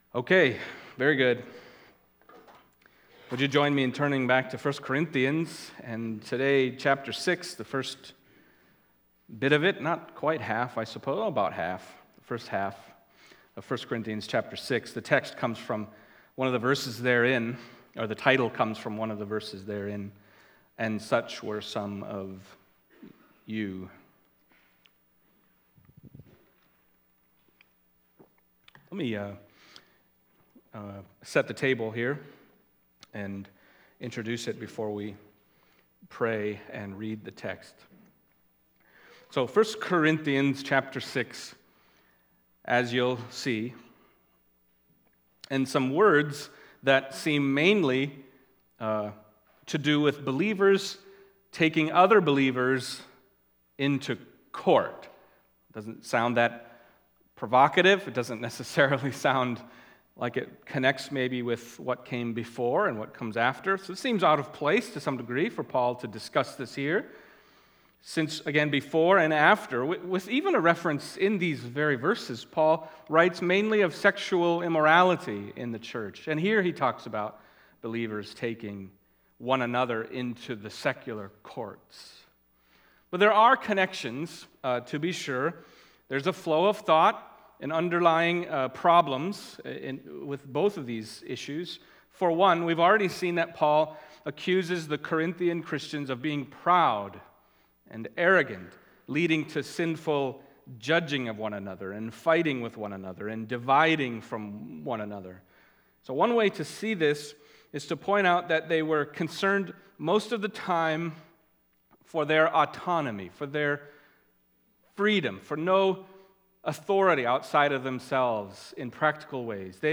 Passage: 1 Corinthians 6:1-11 Service Type: Sunday Morning